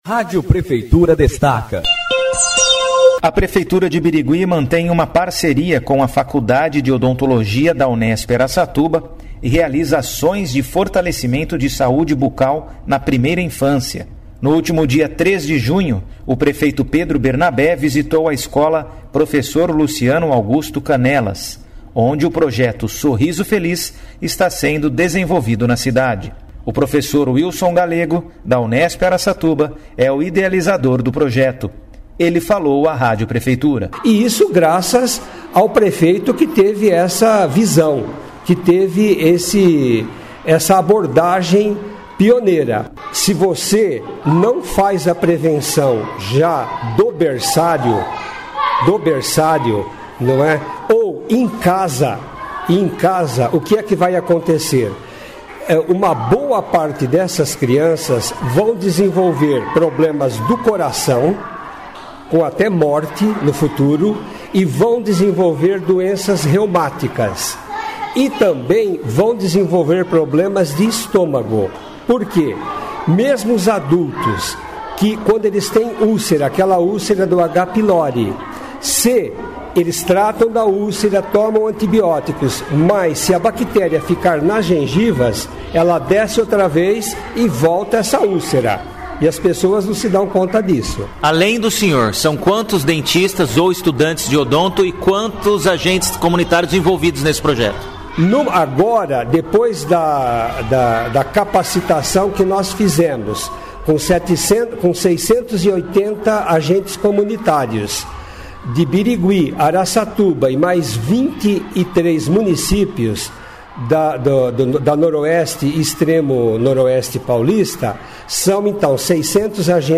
Sonora: